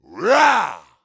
attack_2.wav